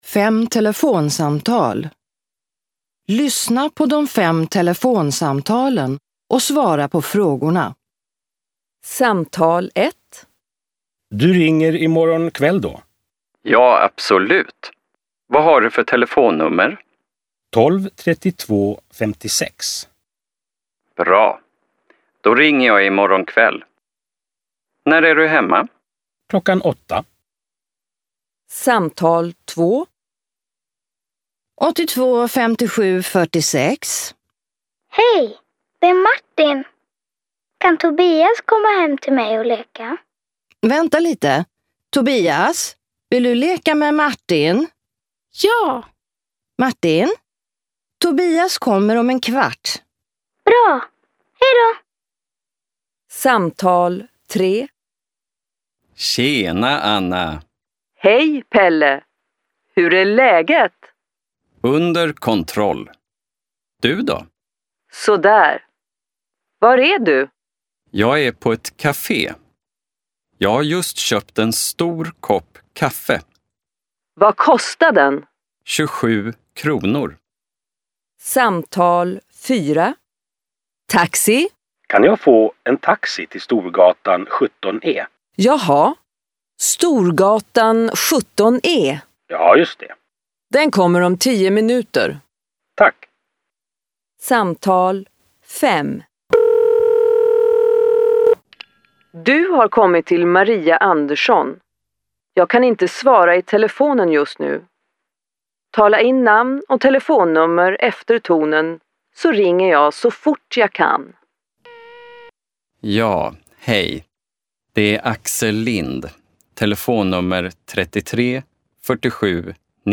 Luisteroefening
Telefoongesprekken
18 18 Fem telefonsamtal.mp3